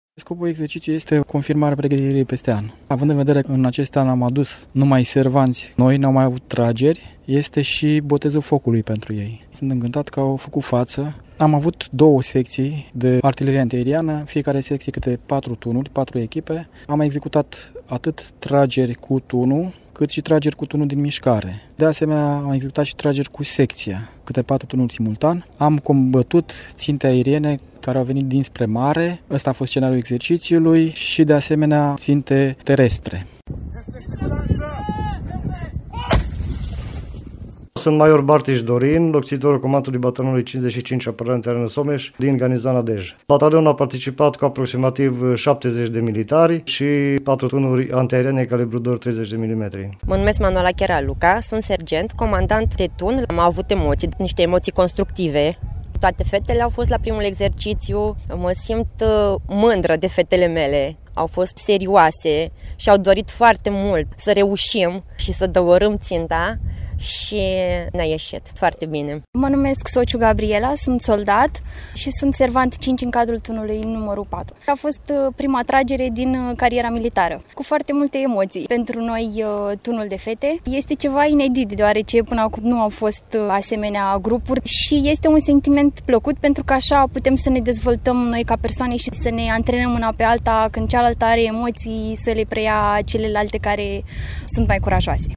Reportaj-Capu-Midia-Noiembrie-2021-OK.mp3